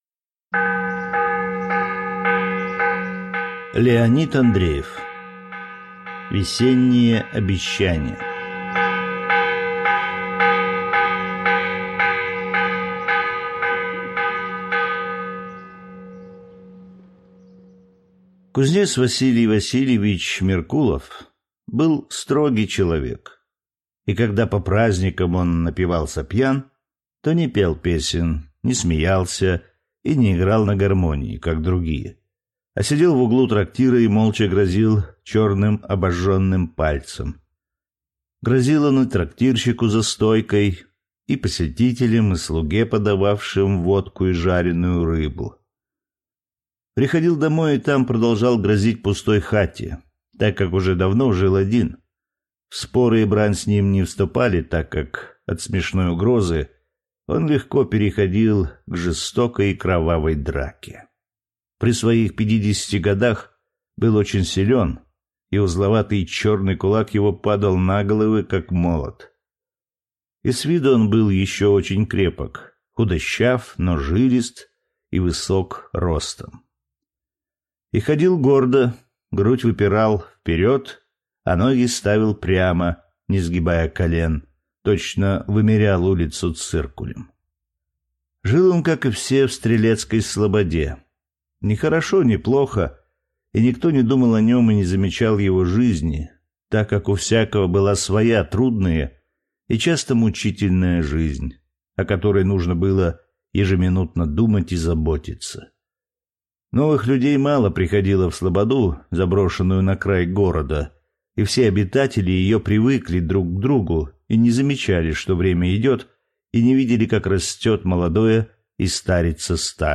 Аудиокнига Весенние обещания